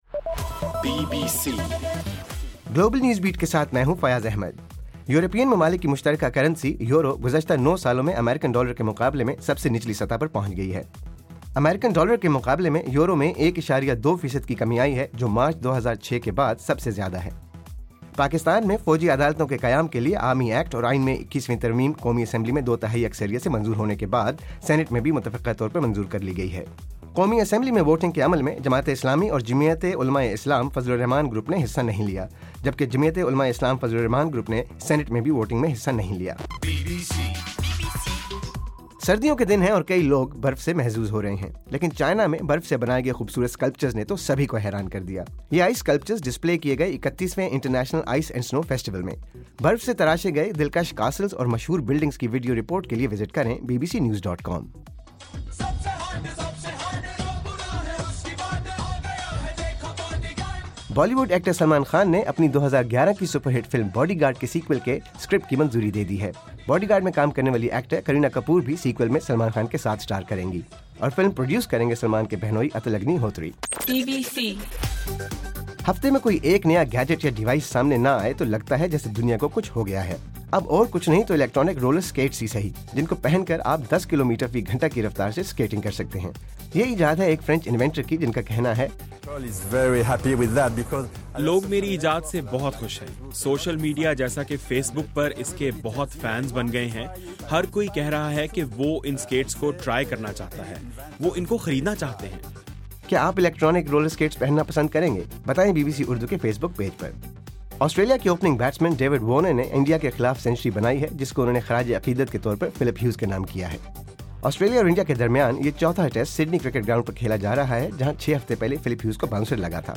جنوری 06: رات 9 بجے کا گلوبل نیوز بیٹ بُلیٹن